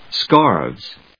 /skάɚvz(米国英語), skάːvz(英国英語)/